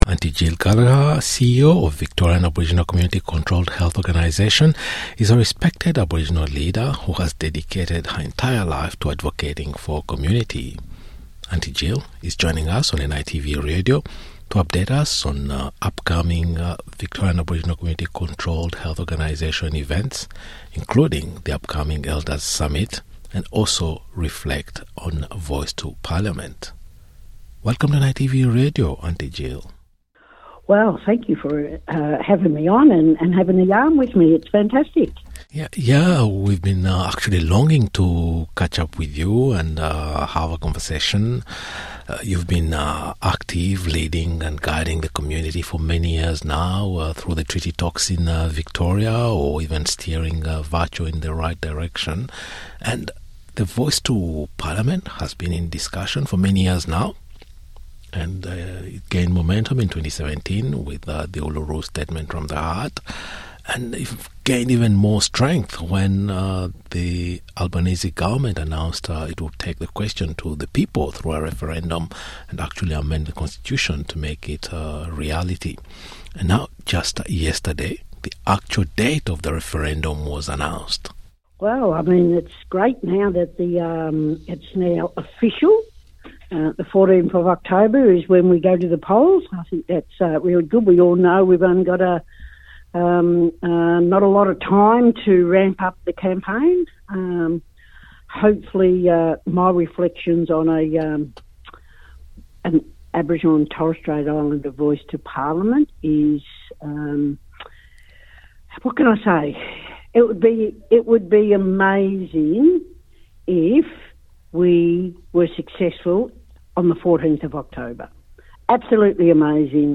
Conversation, with respected Aboriginal leader Aunty Jill Gallagher - CEO of the Victorian Aboriginal Community Controlled Health Organisation (VACCHO) - about the upcoming referendum on Indigenous Voice to Parliament, how Aboriginal Elders drive the narratives as well as an upcoming VACCHO Elder's Summit. Aunty Jill explains emphatically why a Yes vote is crucial.